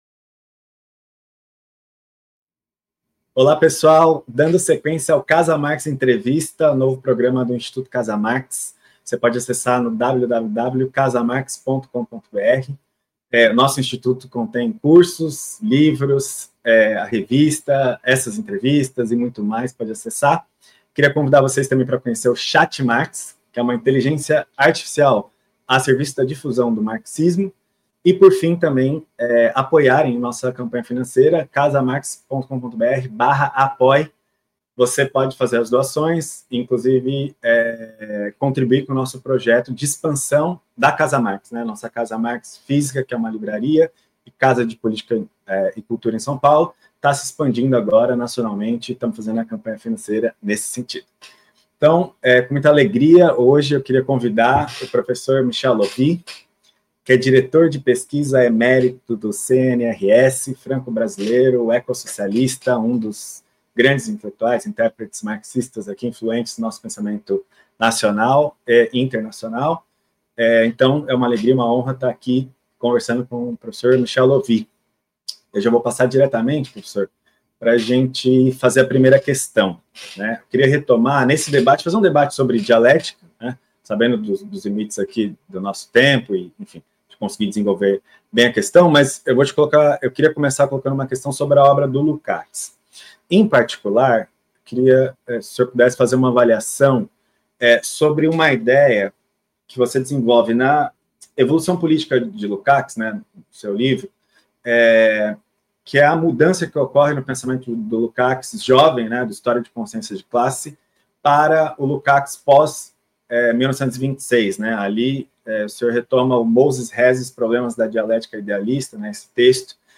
Dialética e marxismo | Ep. 10 - Entrevista com Michael Löwy